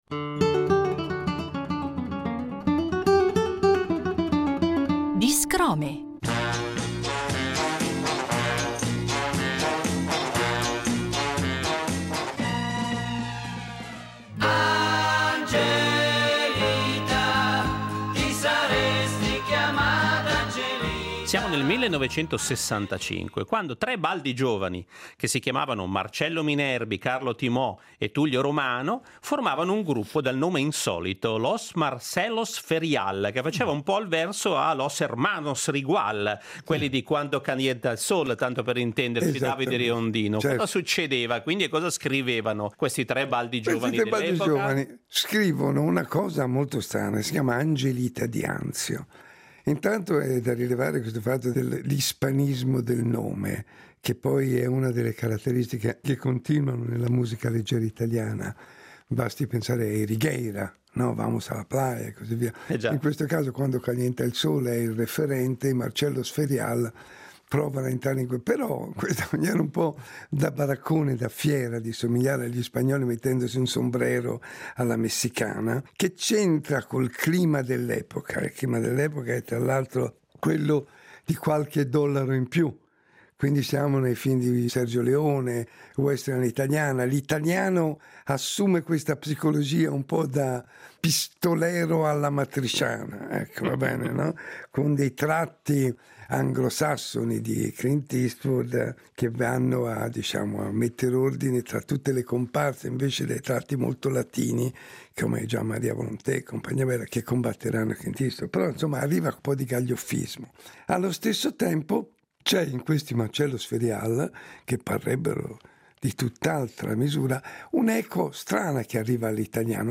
Percorriamo questi anni a suon di giradischi con i mirabolanti racconti di David Riondino